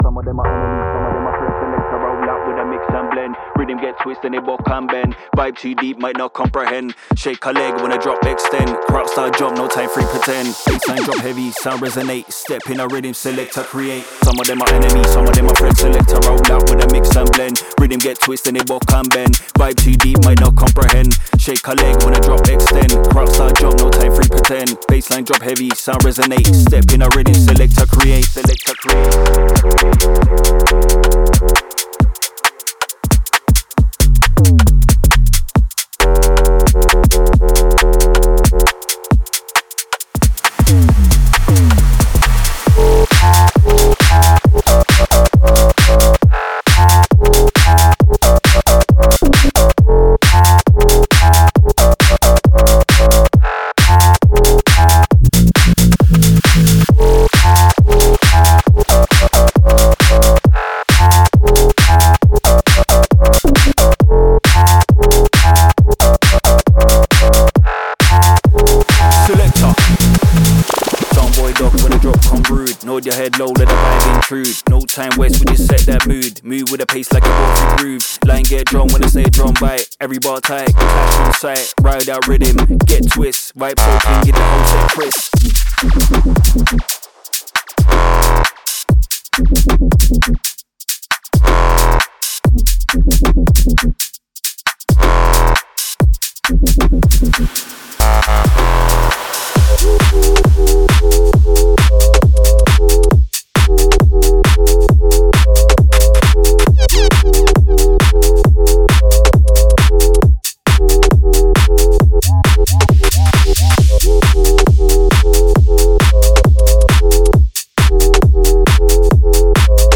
Genre:Garage
このハイエナジーなUKガラージサンプルパックで、サウンドを一気に加速させる準備をしてください。
スキップ感のある2ステップローラーから、重量感のある4x4ストンパーまで、あらゆるスタイルを網羅しています。